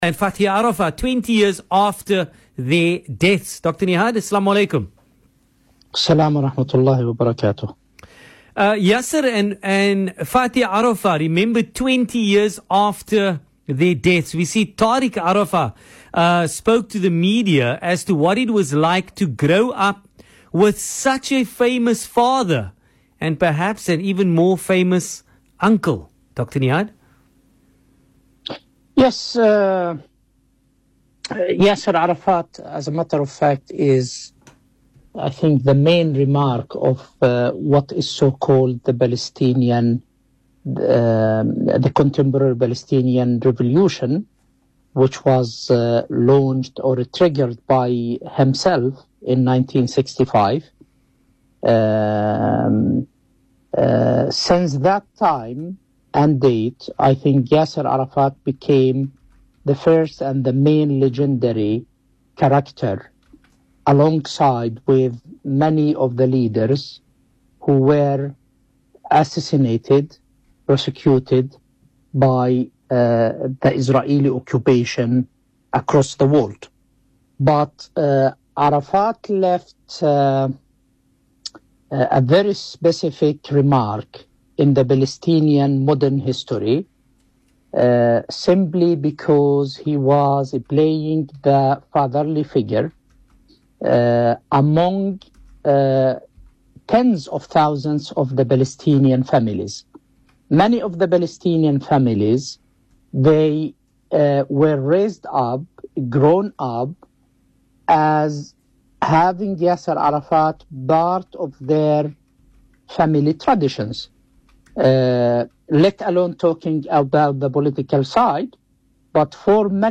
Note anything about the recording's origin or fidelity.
Speaking on VOC’s PM Drive show